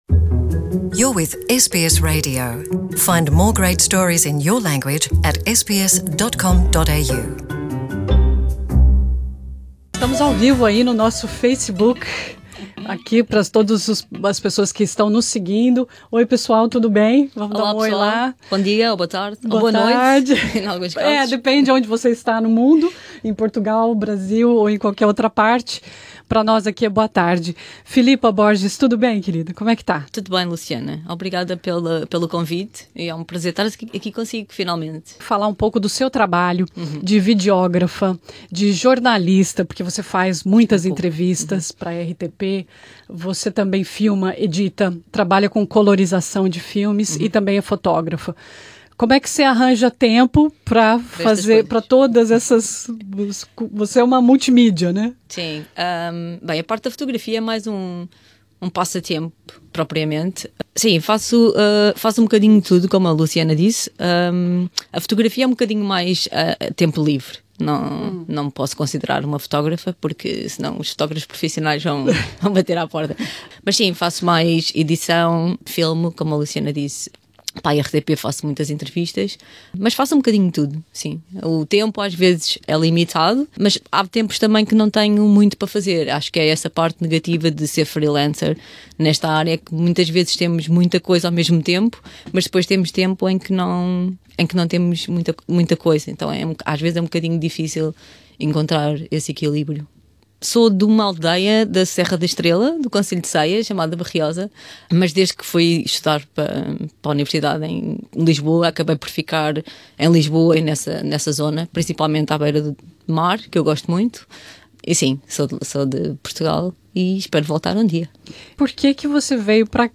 Nessa entrevista